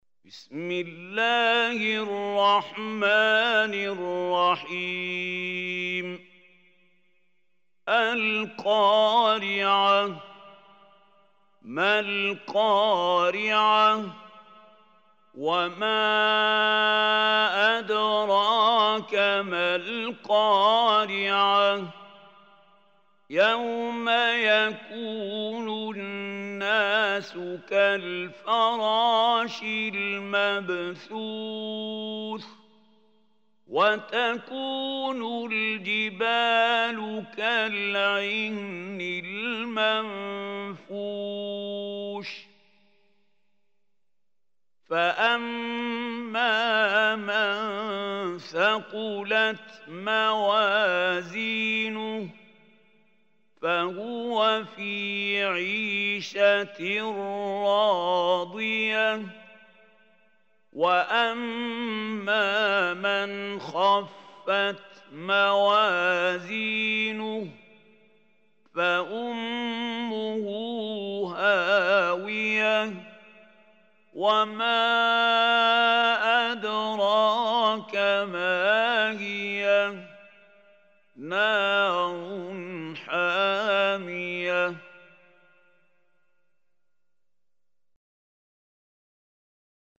Surah Al Qariah Recitation by Mahmoud Khalil
Surah Al Qariah, is 101 surah of Holy Quran. Listen or play online mp3 tilawat / recitation in Arabic in the beautiful voice of Sheikh Mahmoud Khalil Hussary.